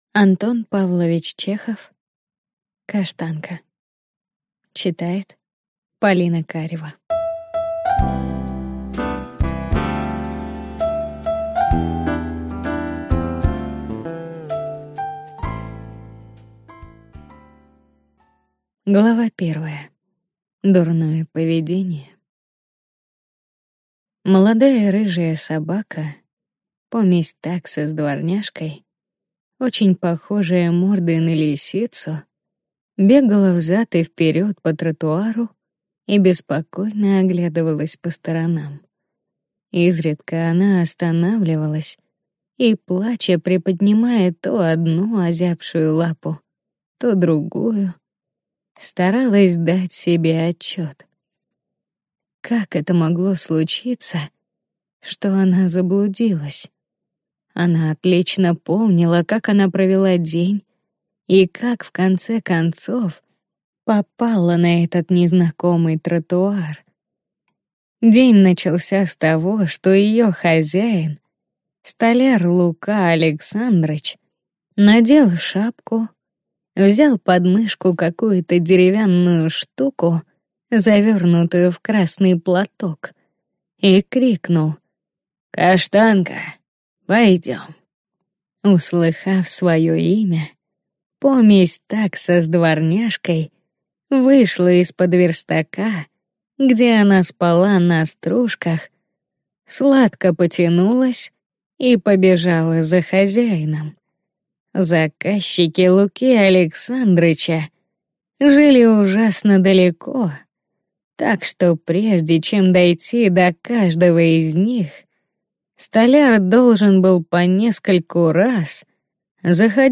Аудиокнига Каштанка